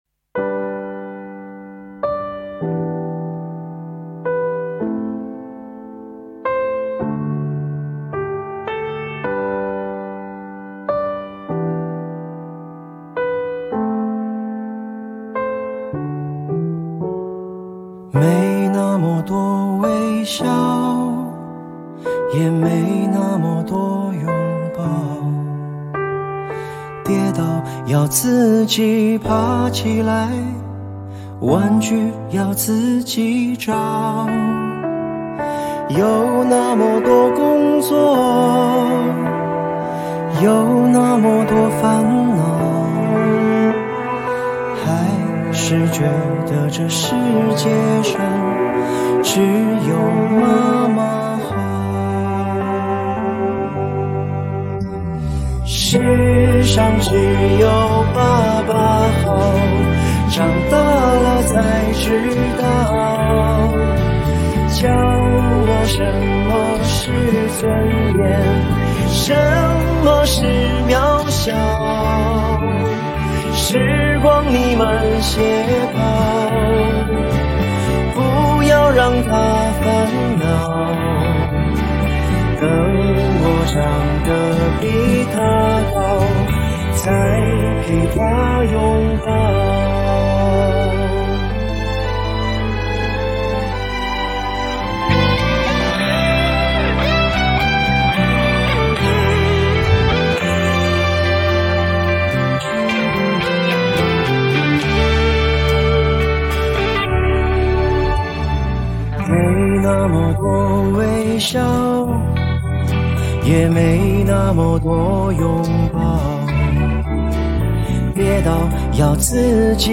4/4 60以下
华语